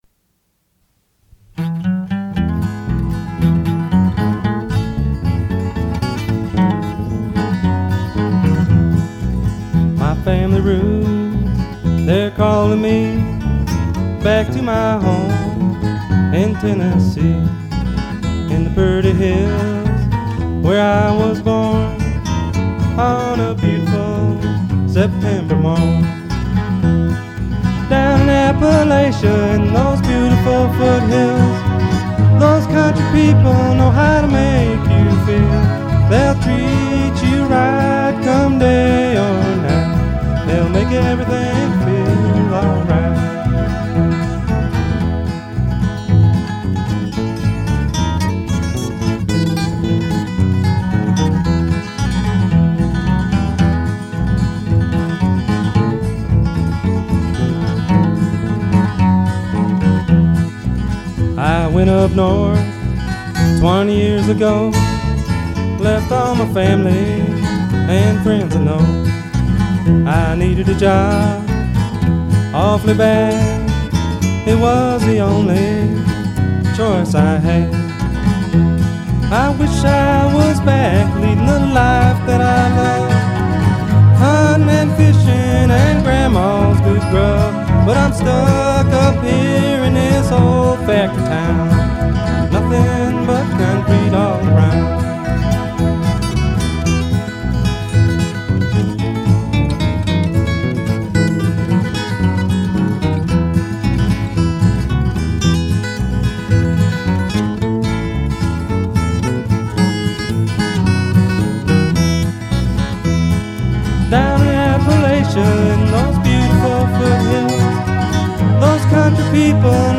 He wrote the songs and was the band’s lead singer and guitar player.